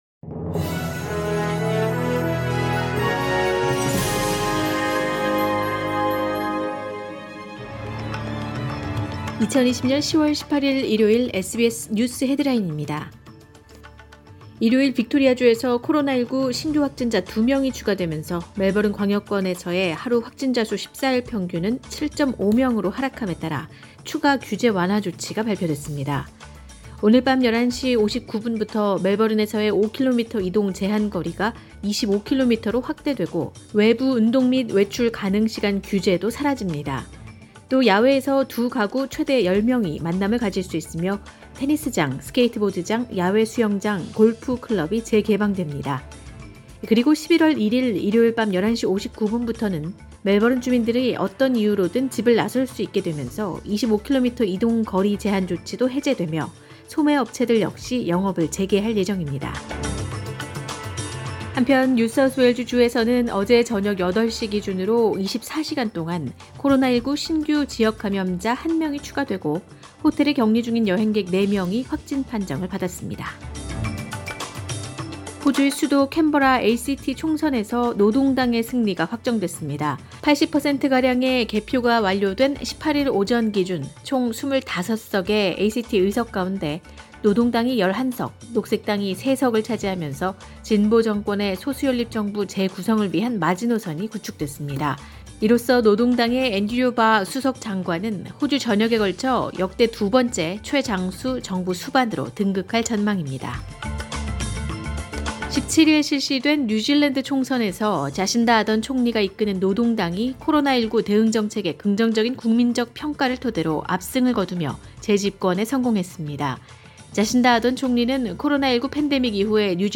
2020년 10월 18일 일요일 오전의 SBS 뉴스 헤드라인입니다.